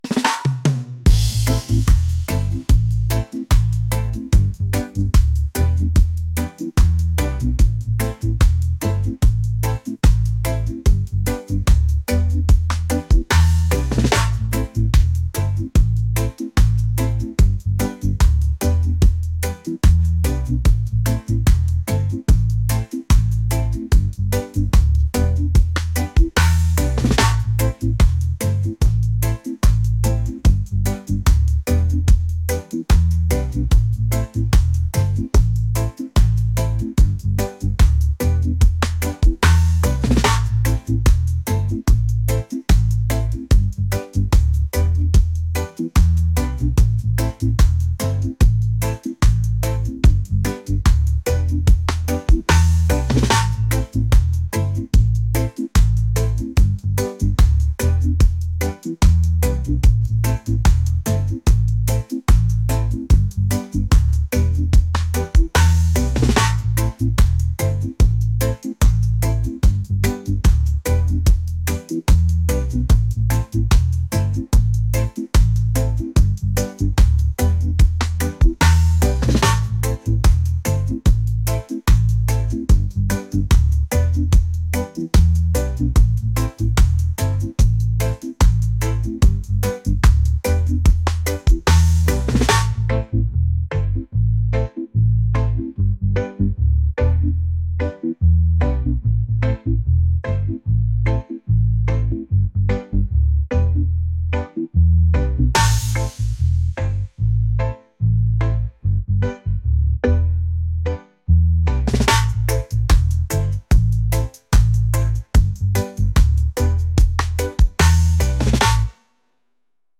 reggae | folk | soul & rnb